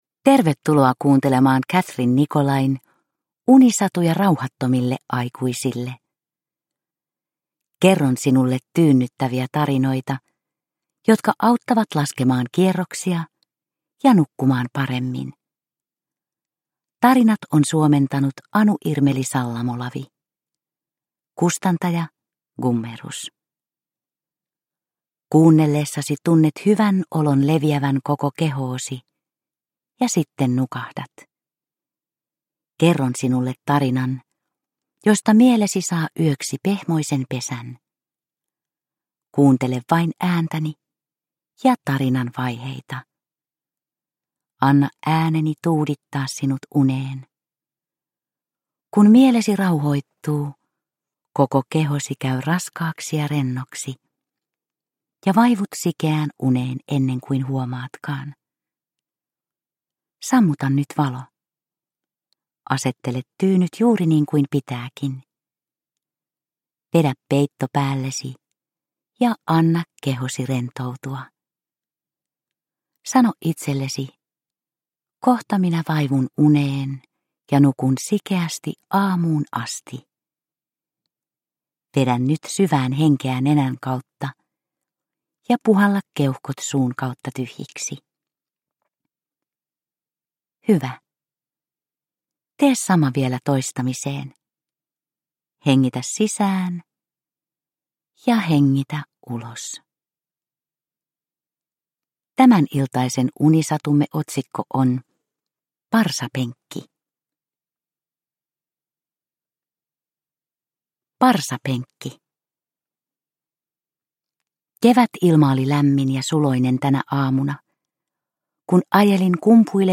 Unisatuja rauhattomille aikuisille 25 - Parsapenkki – Ljudbok – Laddas ner